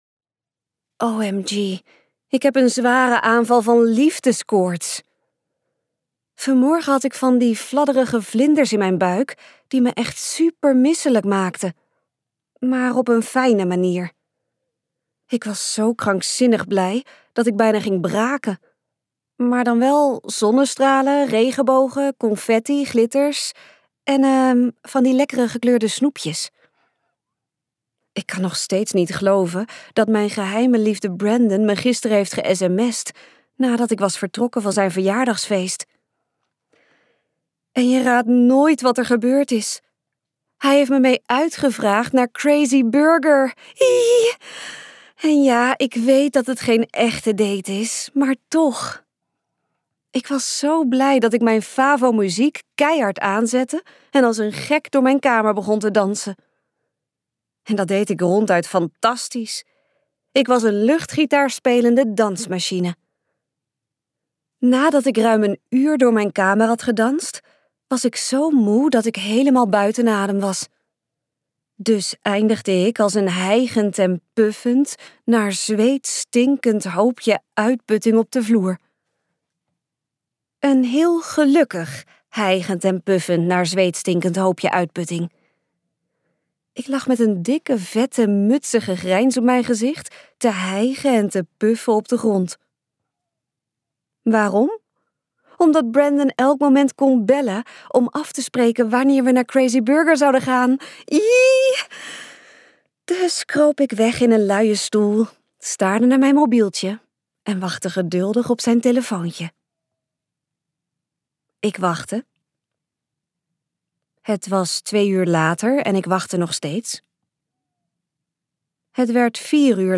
Uitgeverij De Fontein | Hopeloos verliefd luisterboek